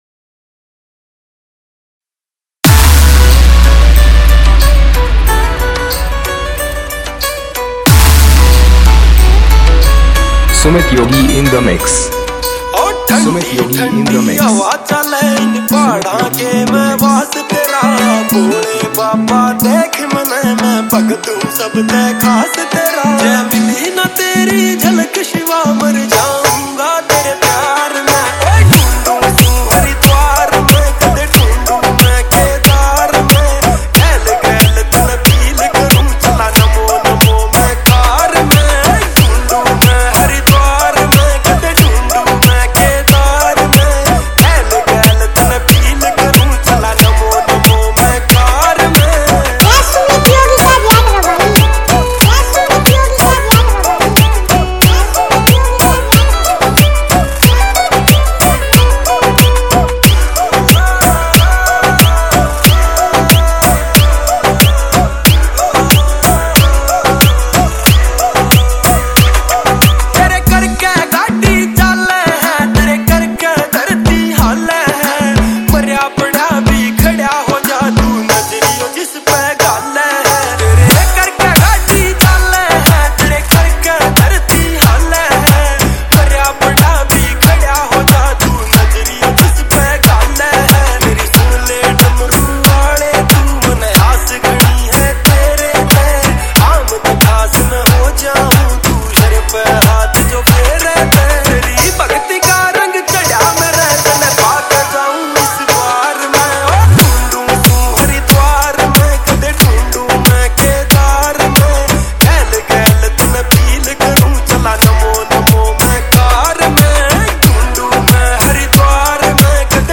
Shiv Dak Kawad Dj Song